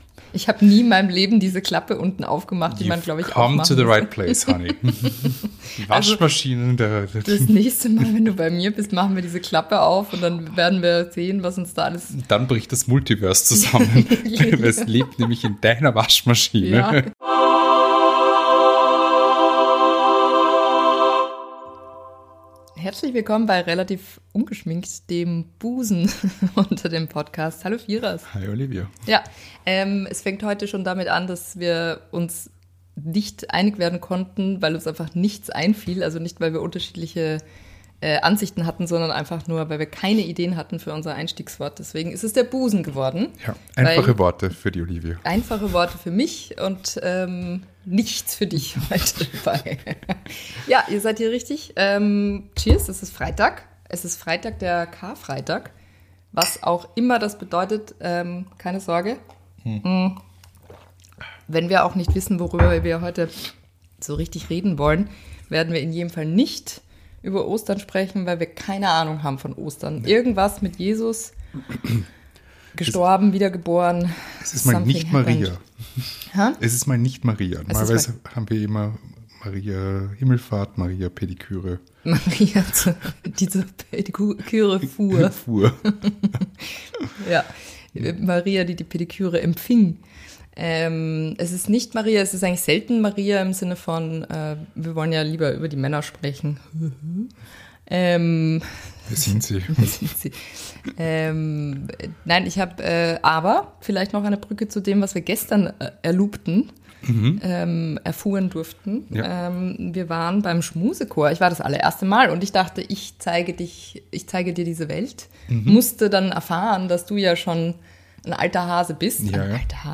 Intro: Sound Effect